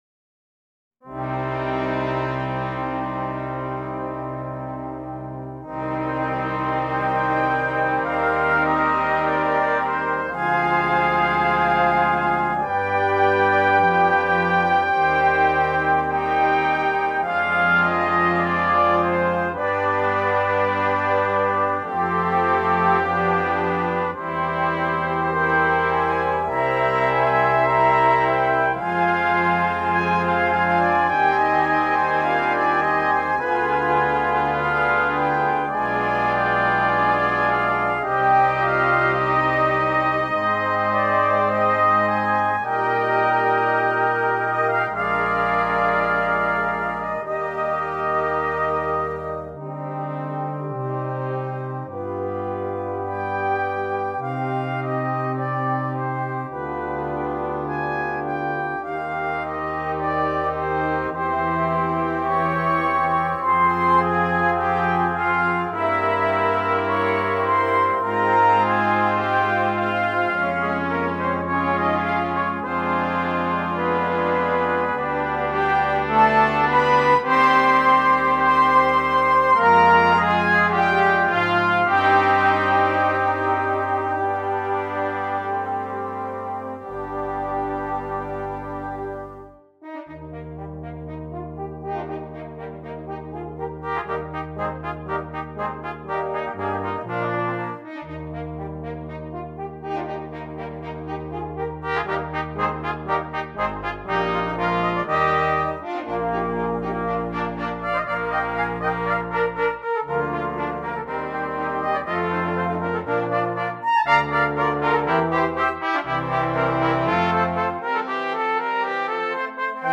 Brass Choir (4.2.2.1.1)